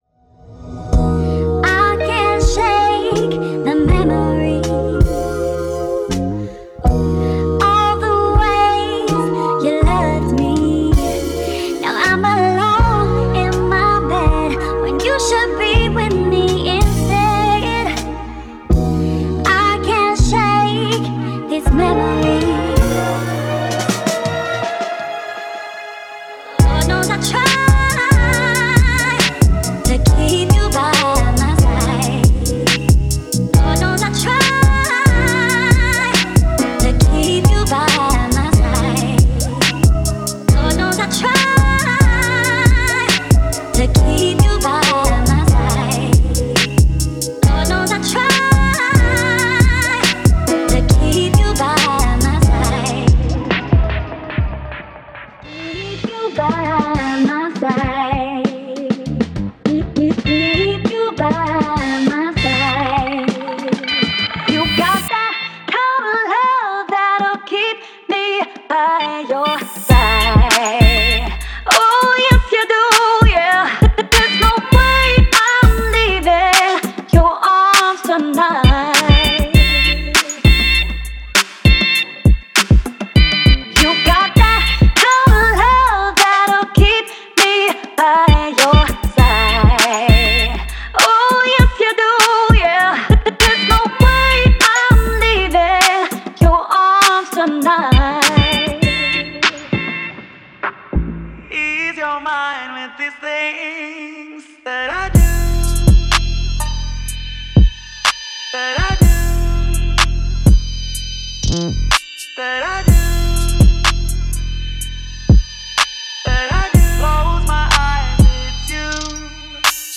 Genre:Trap
中心にあるのは、ギター、ホーン、ストリングス、ピアノ、ボーカルによる完全なライブ演奏を収録したオリジナルサンプルです。
デモサウンドはコチラ↓